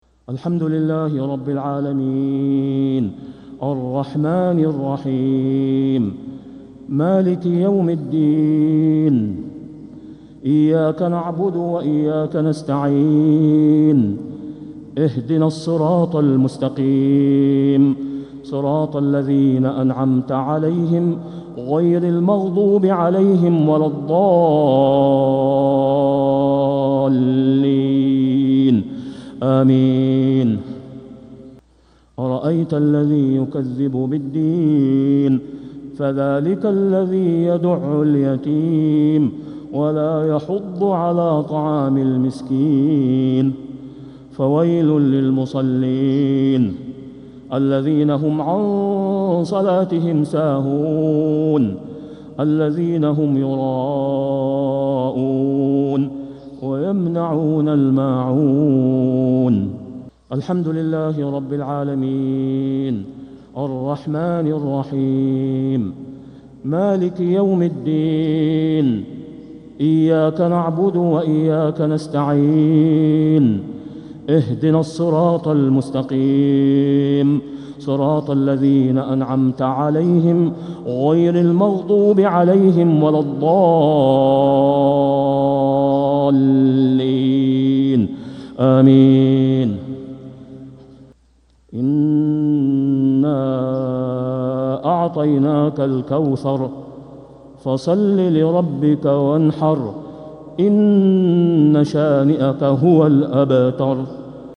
صلاة الجمعة 3-7-1446هـ سورتي الماعون و الكوثر كاملة | Jumu'ah prayer from Surah Al-Maa'un and Al-Kawthar 3-1-2025 🎙 > 1446 🕋 > الفروض - تلاوات الحرمين